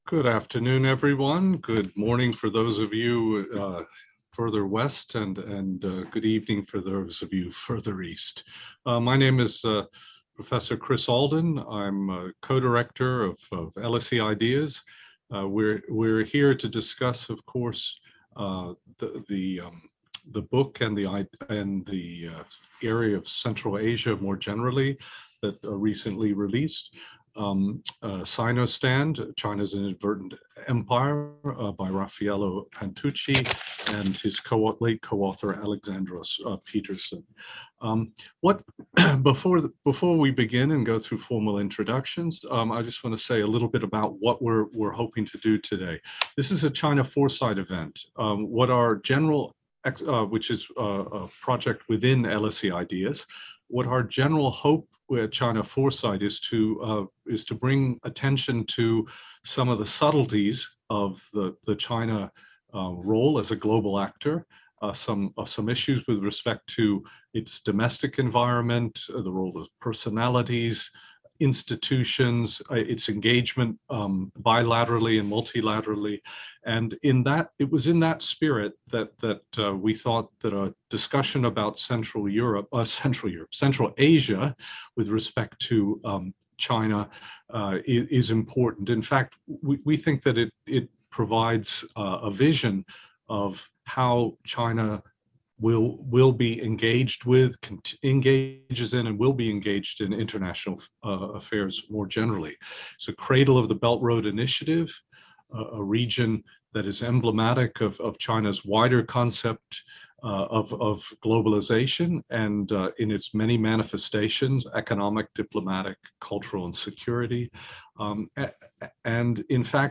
What can Central Asia tell us about China's transformative vision for international affairs? Watch the recording from our webinar.